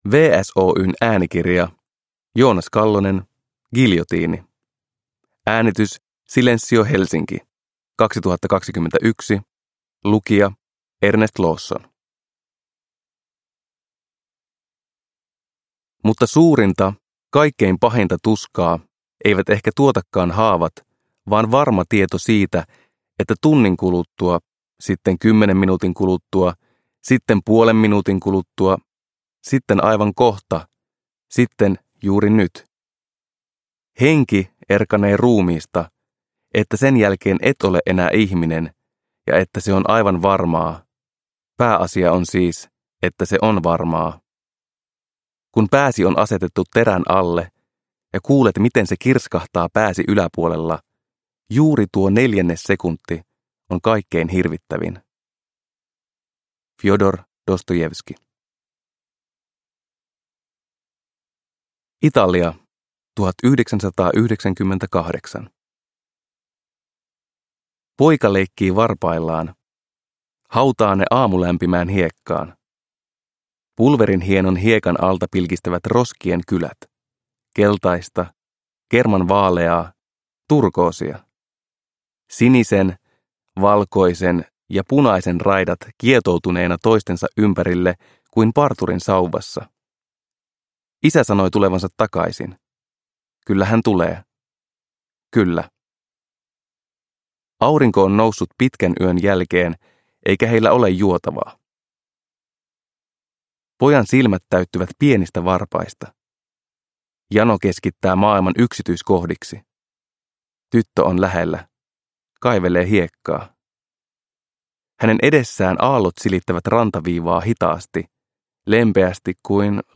Giljotiini – Ljudbok – Laddas ner